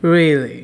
“Really?” vs. its sarcastic counterpart
The latter exhibits at least two orders of non-verbal information: a rhetorical question and a mocking attitude.
really_sarcasm.wav